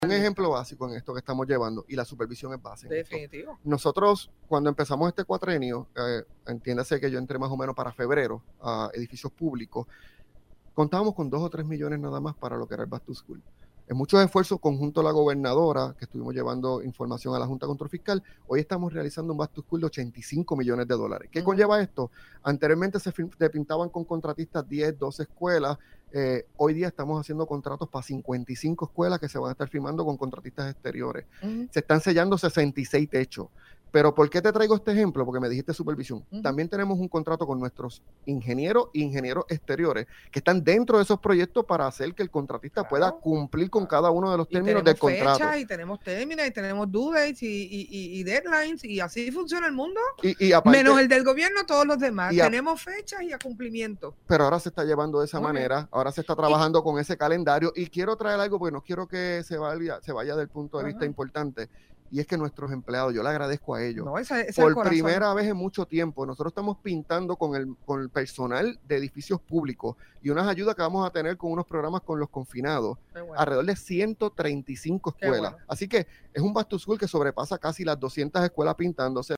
Tanto así es que nosotros, por el mantenimiento de lo que tú me estás mencionando, empezamos una iniciativa que la gobernadora nos dio […] de trabajarla, que el Back to School lo empezáramos desde marzo“, indicó Lasalle en el programa El Calentón.